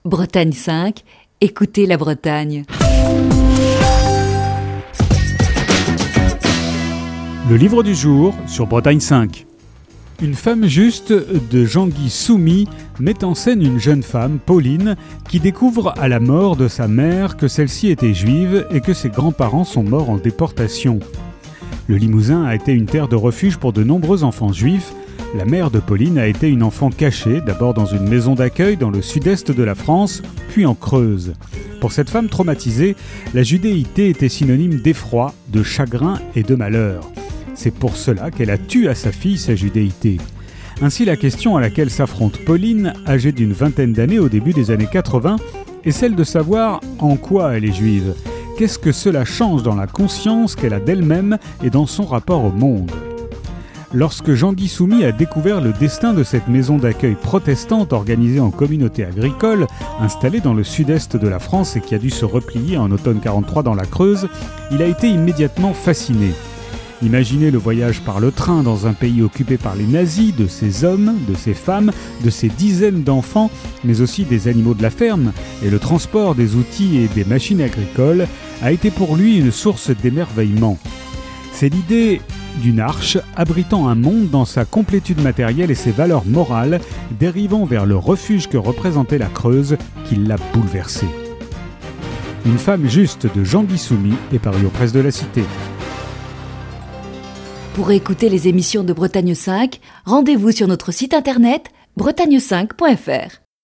Chronique du 17 septembre 2020.